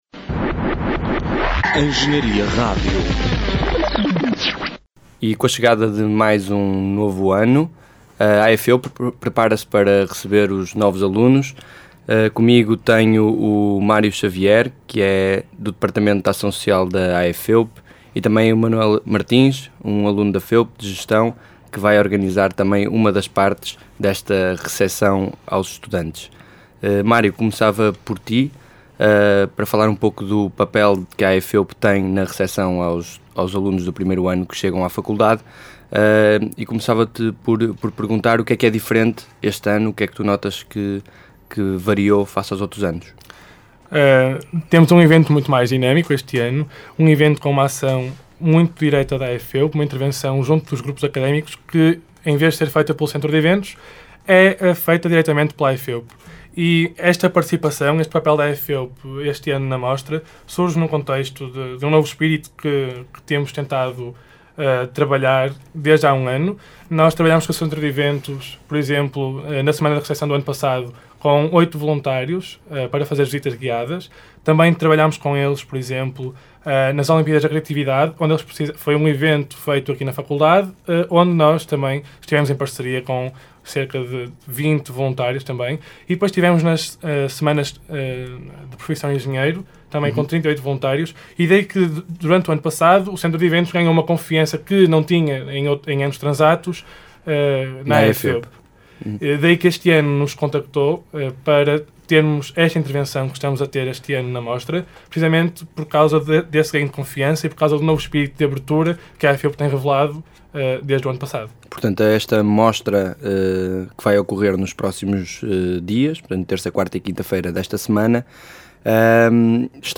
entrevista-AEFEUP_mixdown.mp3